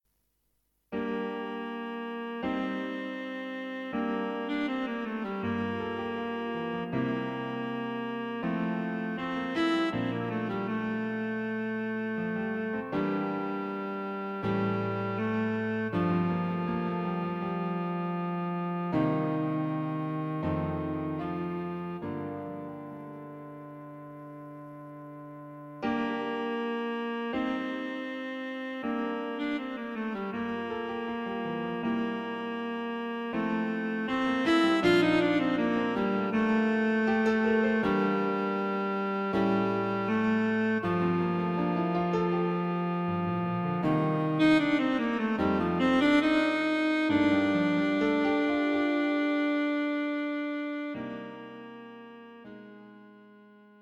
Cello and Piano This piece has a calming effect.